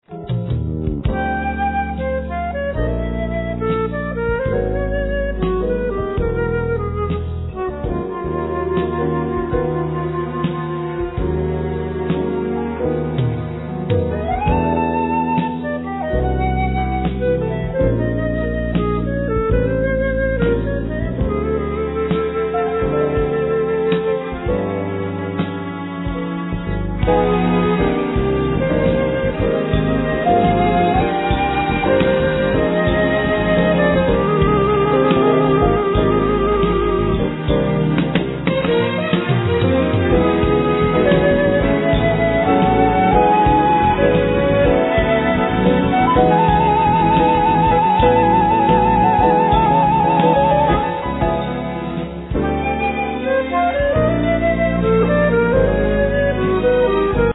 Soprano & Alto saxophone, Flute, Piano, Voice
Vibraphone, Voice, Glockenspiel
Drums, Percussions